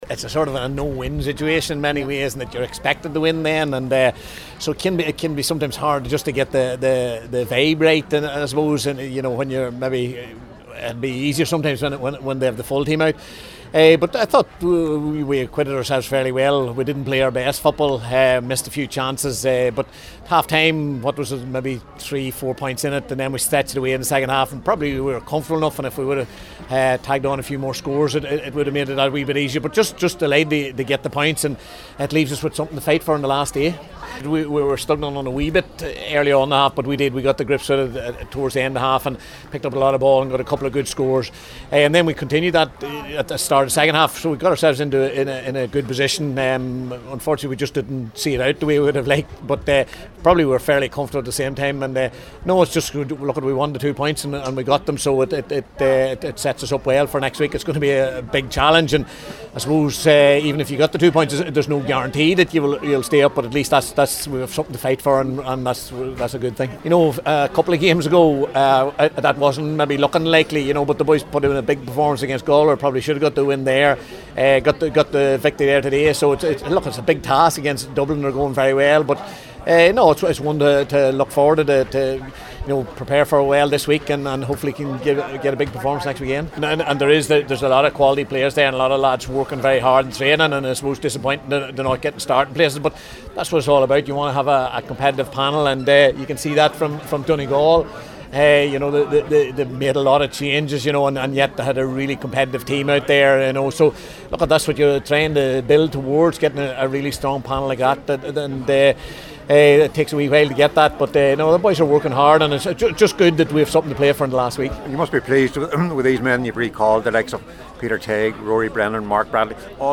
O’Rourke spoke to the assembled media after the game…